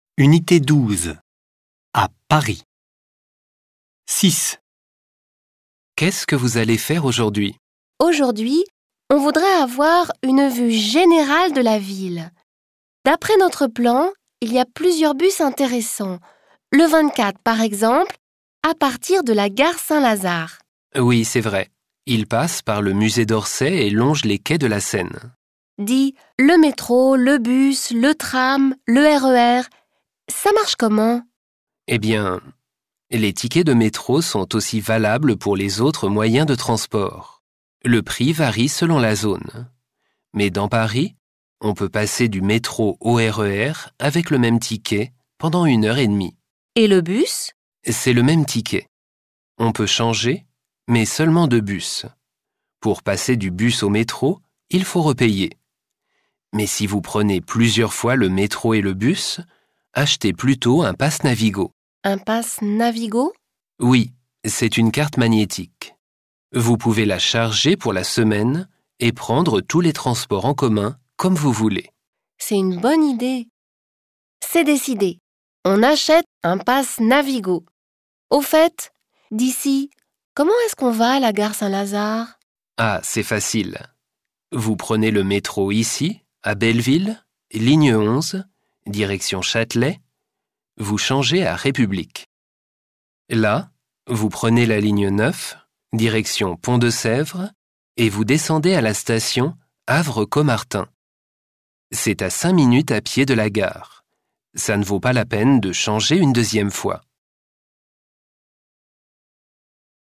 Folgende Hördialoge stehen zur Verfügung: